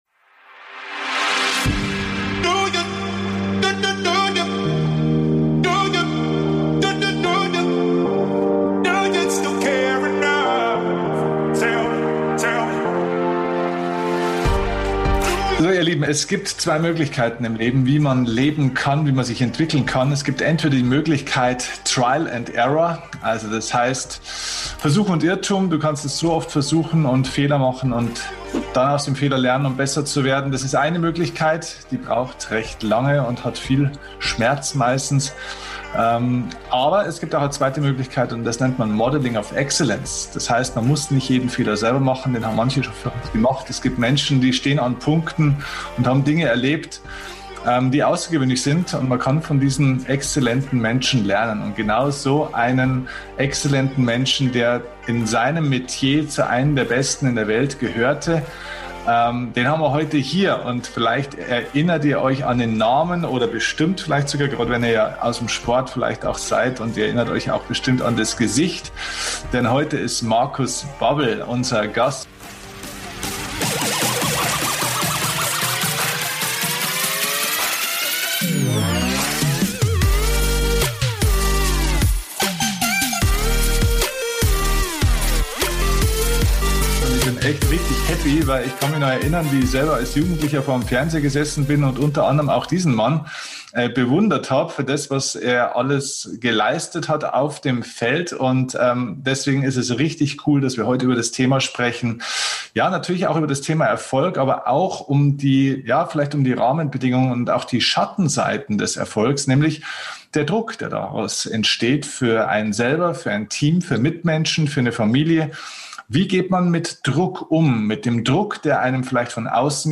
#355 So gehst Du mit hohem Druck um - Interview mit Fußballstar Markus Babbel | Leistungsdruck | Erwartung | Selbstbewusstsein ~ DIE KUNST ZU LEBEN - Dein Podcast für Lebensglück, moderne Spiritualität, emotionale Freiheit und berufliche Erfüllung Podcast